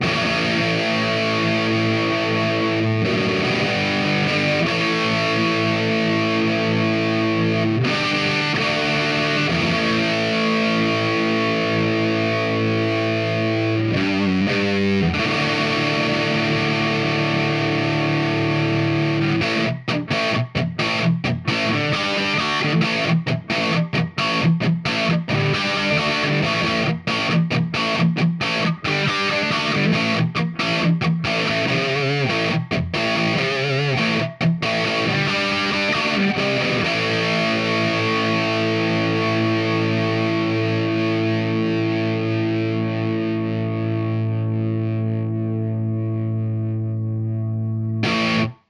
Même rig, mais avec un clone BYOC de MXR Distortion+:
Les Paul Junior, Marshall Superlead et GT500.mp3